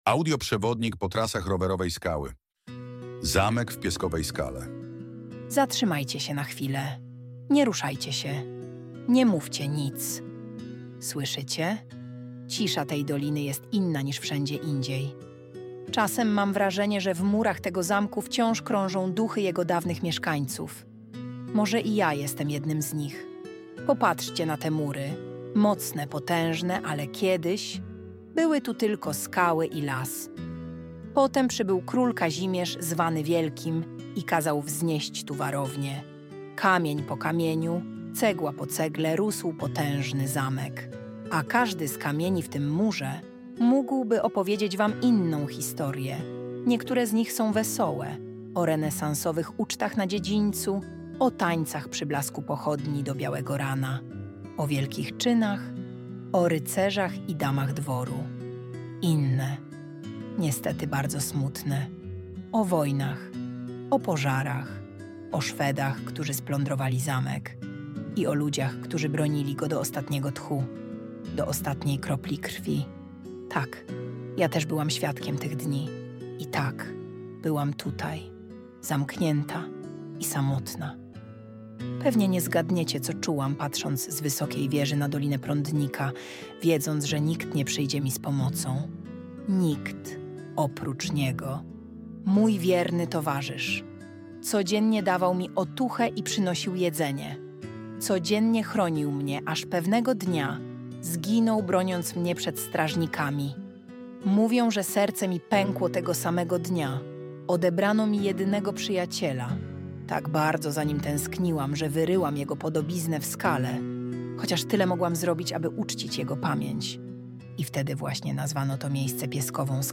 Audio-przewodnik---Zamek-w-Pieskowej-Skale---final-mfr4xbod.mp3